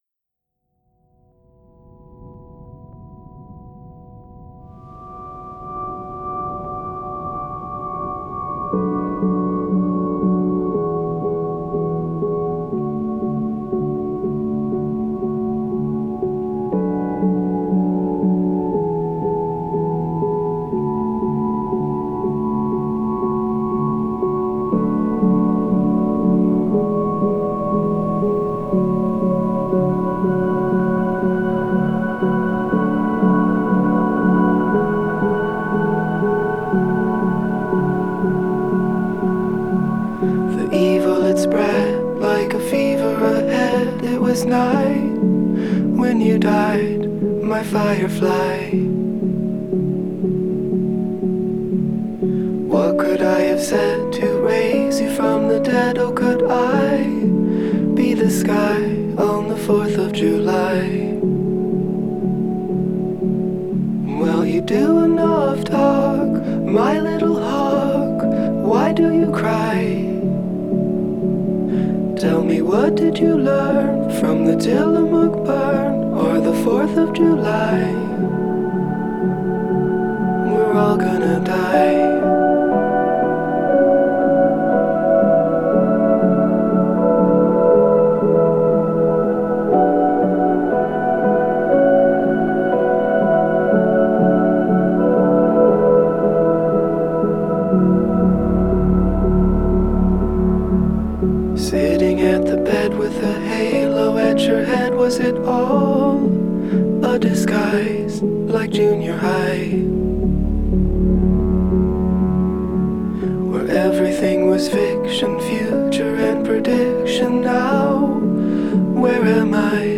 Genre: Indie Folk, Singer-Songwriter